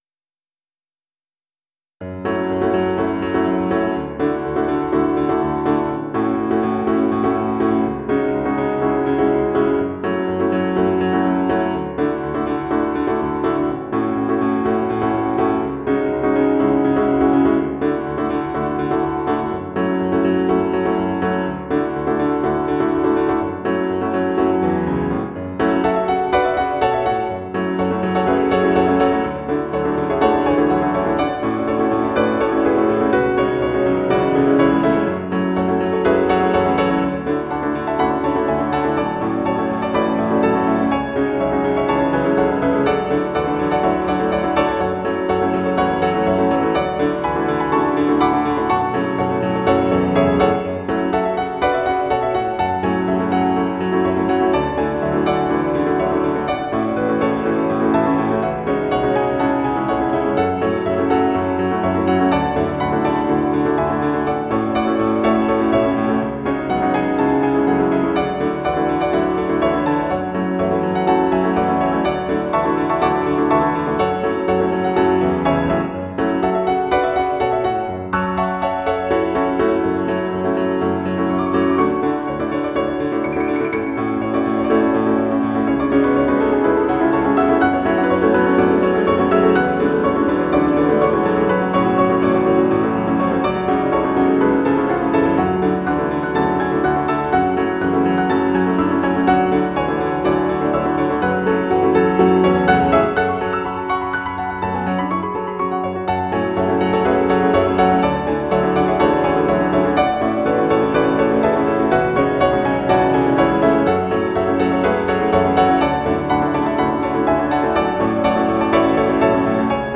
Title Sarsparilla Opus # 18 Year 0000 Duration 00:02:22 Self-Rating 3 Description More than a little chaotic. mp3 download wav download Files
Trio, Piano Plays